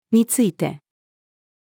について-female.mp3